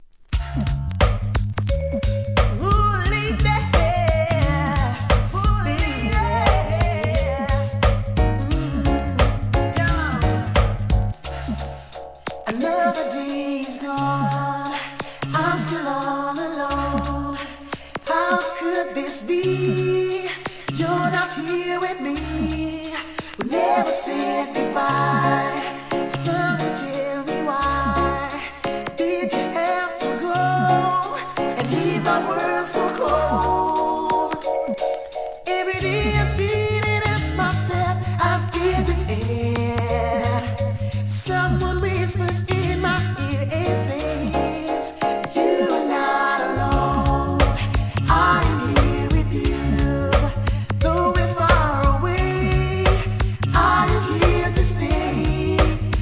REGGAE
ACAPPELLA収録です。